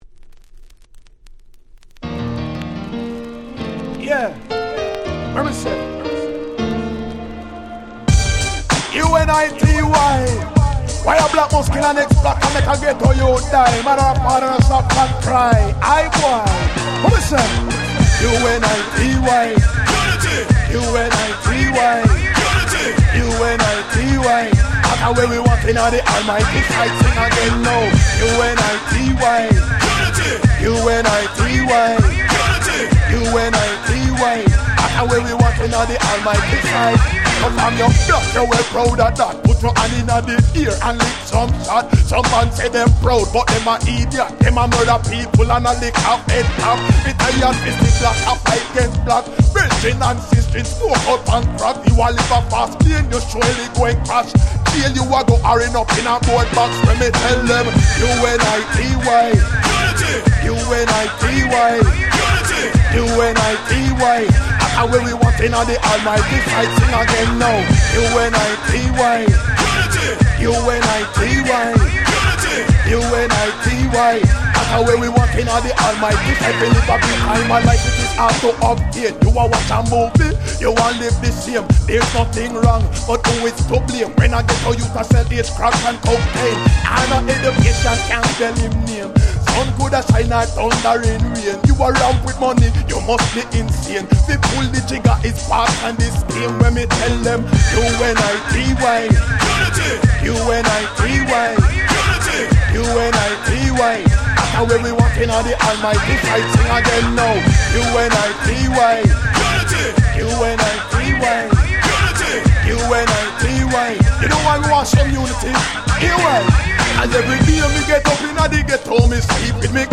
94' Very Nice Reggae / Ragga Hip Hop !!
のBeatを使用したキャッチーなRagga Hip Hop。
ラガマフィン レゲエ Dancehall ダンスホール 90's